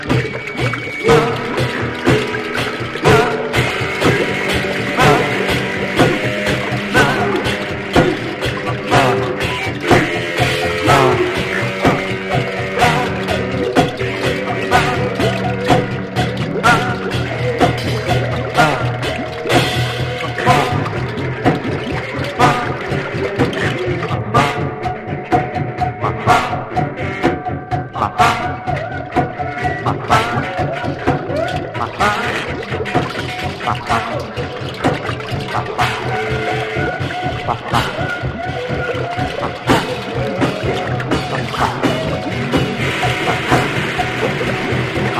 英国カルト・ポストパンク/エクスペリメンタル・バンド唯一のフル・アルバム！